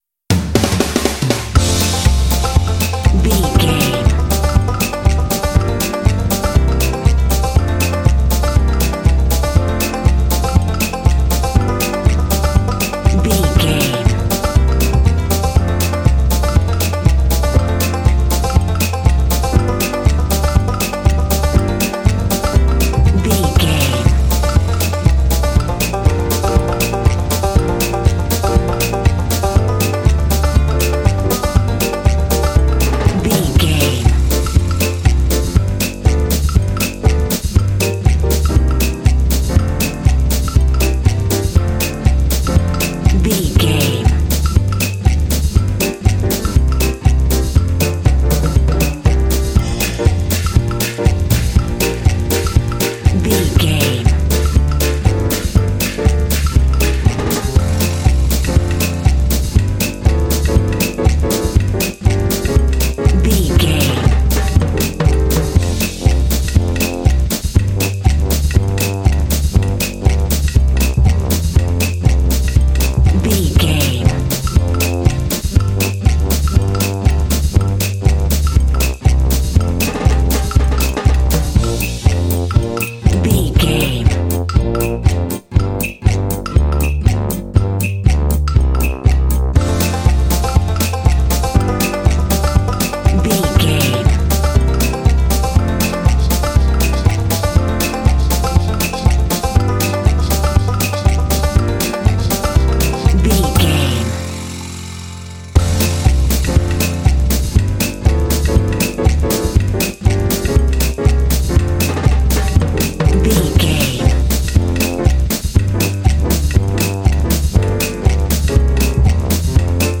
Ionian/Major
playful
cheerful/happy
piano
banjo
bass guitar
drums
brass